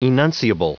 Prononciation du mot enunciable en anglais (fichier audio)
Prononciation du mot : enunciable